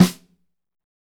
SNR P C S08R.wav